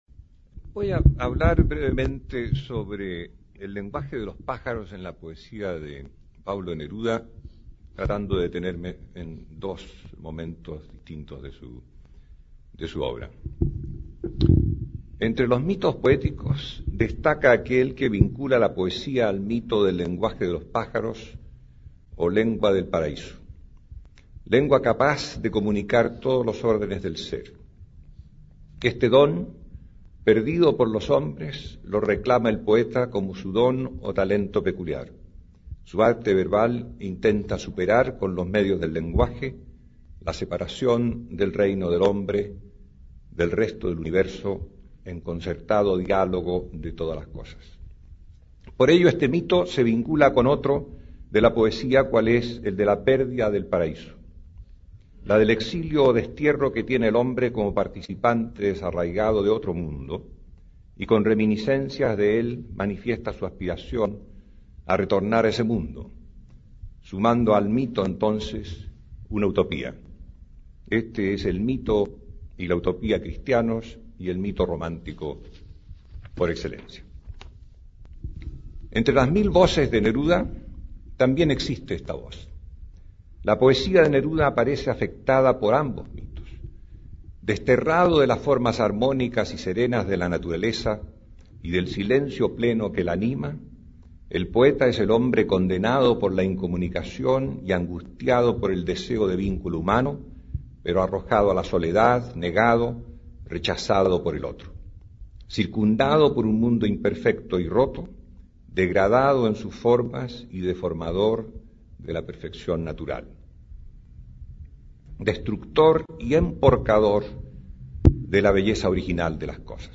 Aquí podrás escuchar una conferencia dictada por el académico Cedomil Goic, experto en literatura hispanoamericana, que aborda el tema de las aves en la poesía de Pablo Neruda. En dicha exposición, que se enmarca dentro de una mesa redonda realizada en torno a la figura del poeta, se da cuenta del mito literario que vincula la poesía al lenguaje de los pájaros -o lengua del paraíso- como don reclamado por los poetas para reestablecer la continuidad entre el hombre y las otras creaciones del universo.